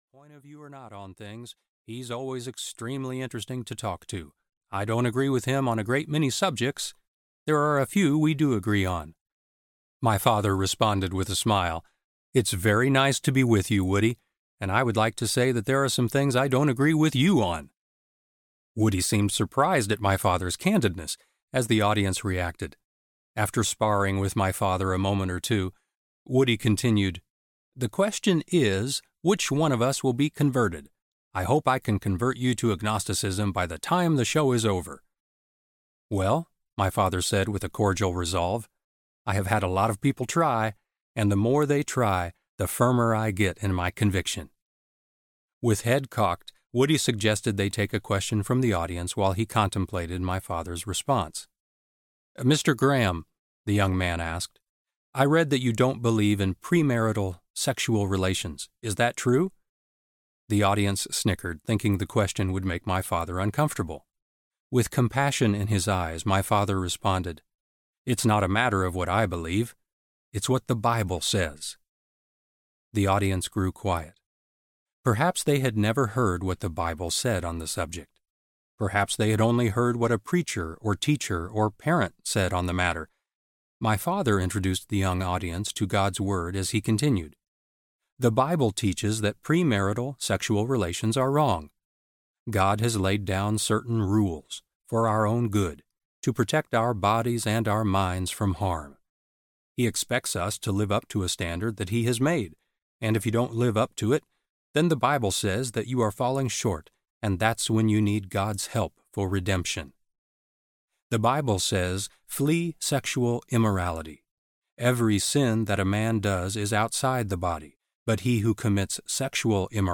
Through My Father’s Eyes Audiobook
10.7 Hrs. – Unabridged